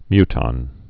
(mytŏn)